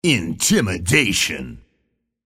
Double Click audio from the Halo: Reach Clicktacular YouTube Masthead.